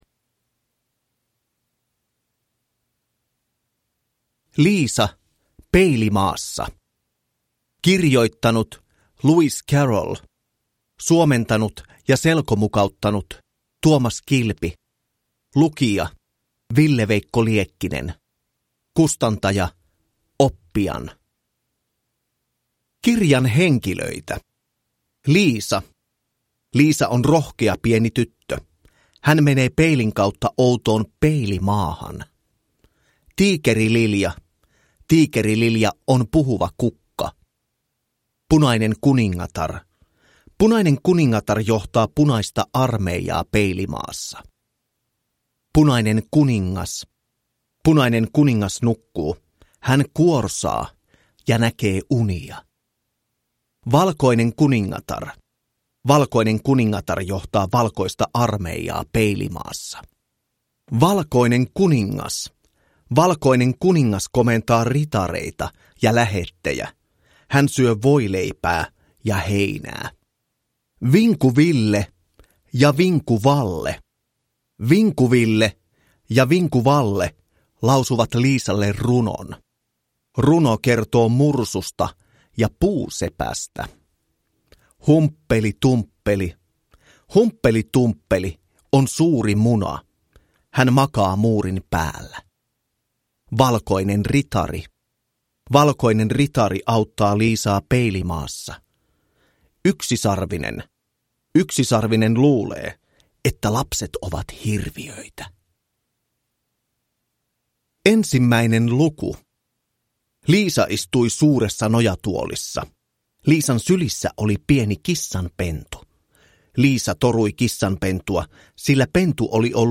Liisa Peilimaassa (selkokirja) – Ljudbok – Laddas ner